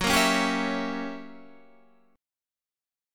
Gb7b9 chord